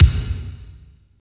32.06 KICK.wav